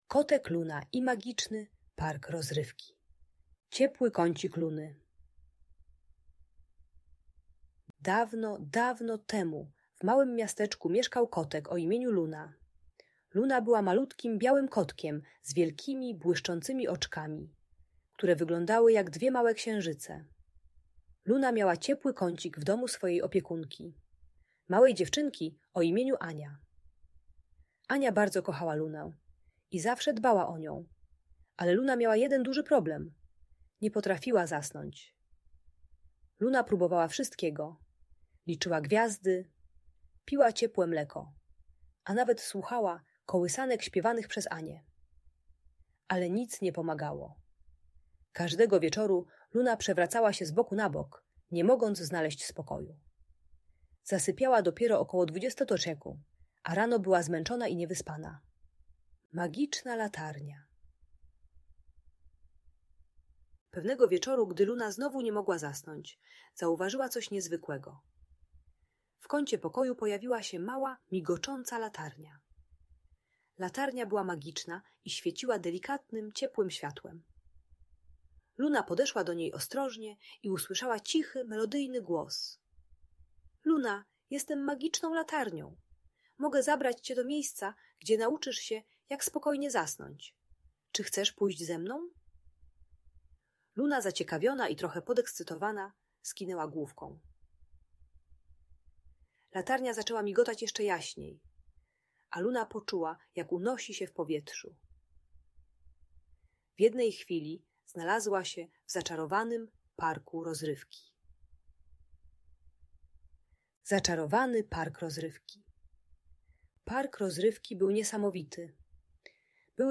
Historia kotka Luny i magicznego parku rozrywki - Audiobajka